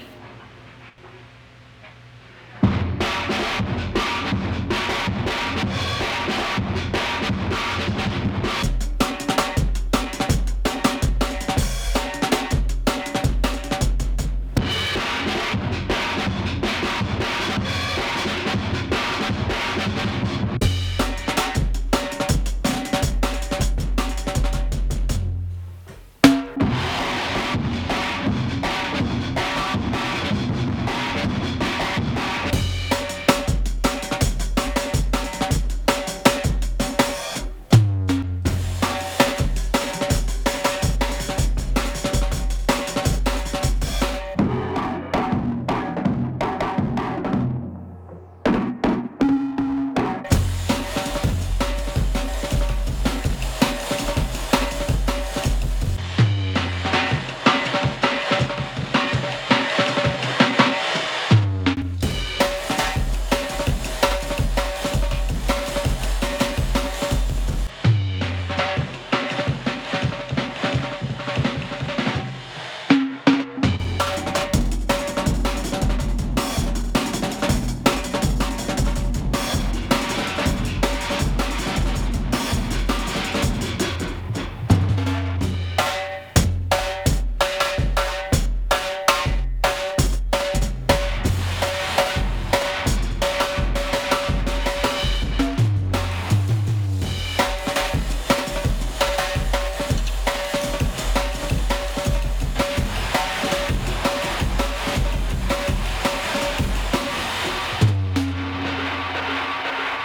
Index of /4 DRUM N BASS:JUNGLE BEATS/BLIND MAN RELAPSES INTO HARD BEATS TILL HE SEES THE LIGHT AGAIN